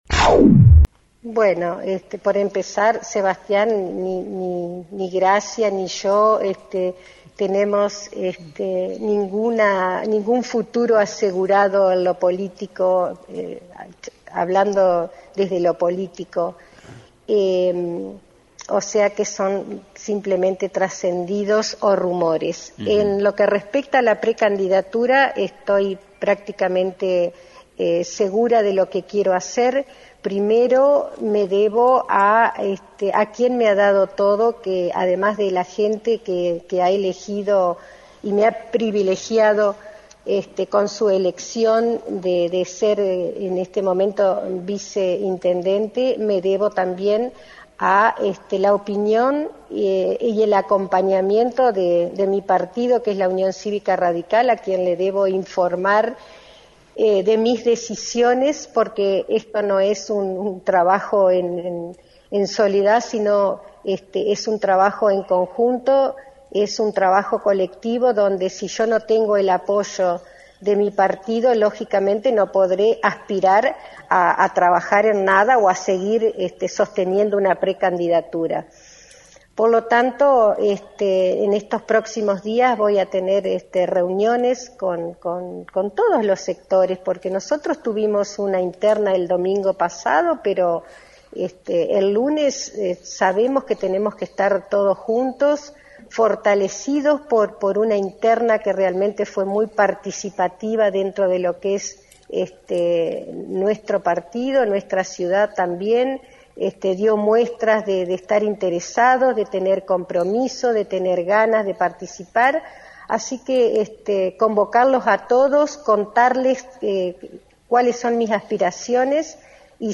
En diálogo con FM 90.3 la viceintendenta Ana Schuth declaró que convocará a los miembros de su partido para pedirles “acompañamiento, ayuda y militancia”.
Ana Schuth – vice Intendente de Victoria